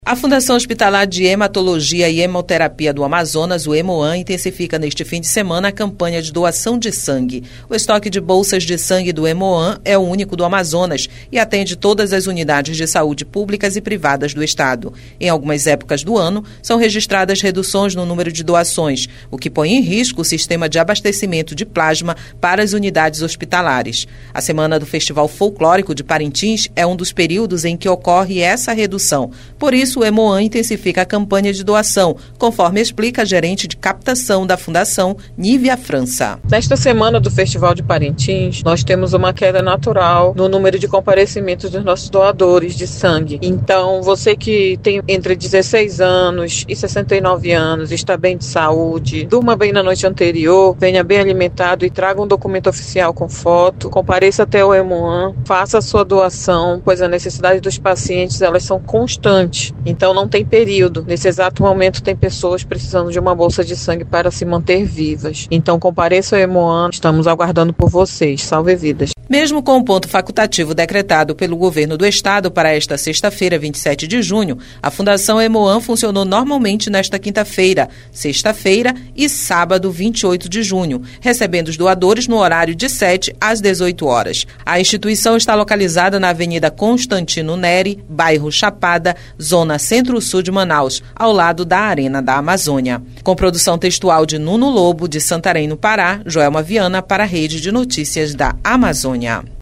O estoque de bolsas de sangue do hemocentro é o único do Amazonas e atende todas as unidades públicas e privadas do estado. Acompanhe a reportagem